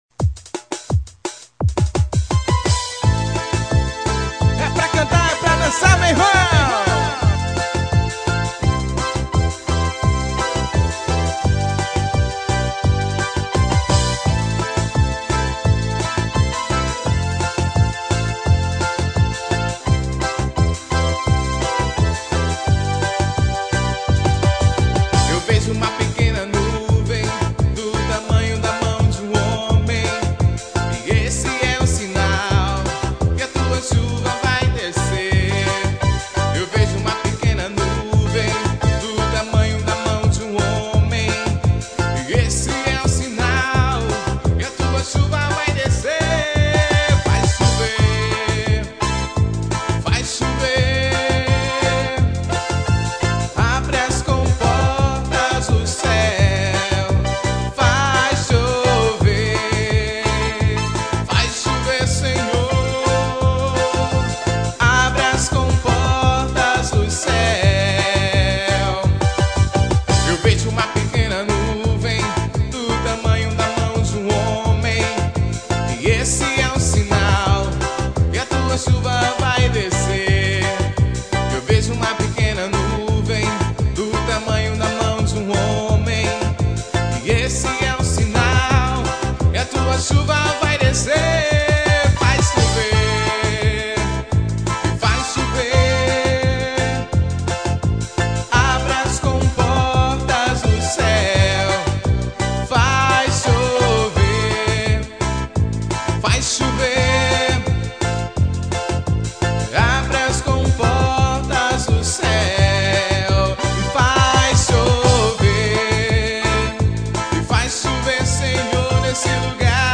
Evangelico.